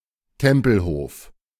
Tempelhof (German: [ˈtɛmpl̩hoːf]